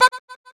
babumbumbum sounds
baBumBumBum_Farther1.wav